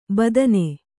♪ badane